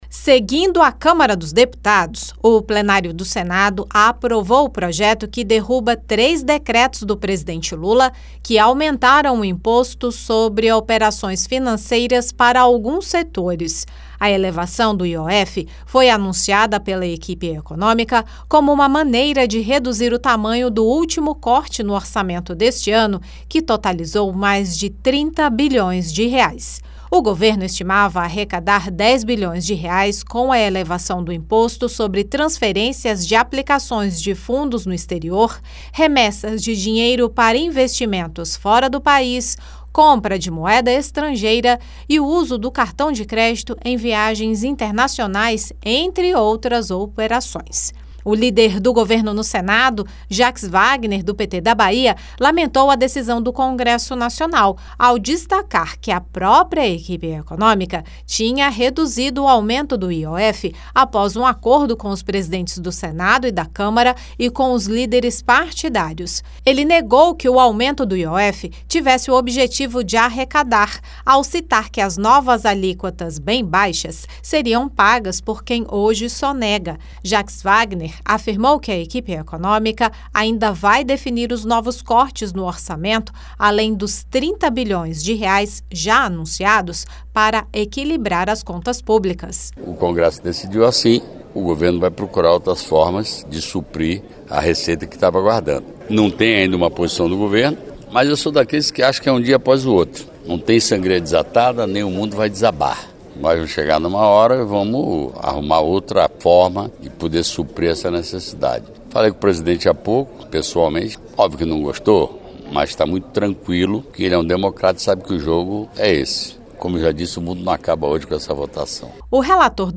Ao lamentar a decisão do Congresso Nacional, o líder do governo no Senado, Jaques Wagner (PT-BA), lembrou que após acordo com os próprios parlamentares, o Ministério da Fazenda reduziu as alíquotas do IOF e o presidente Lula editou uma medida provisória com a taxação das bets e de outras operações financeiras.
Já o relator, senador Izalci Lucas (PL-DF), declarou que a equipe econômica precisa reduzir as despesas e não aumentar impostos para conseguir mais recursos.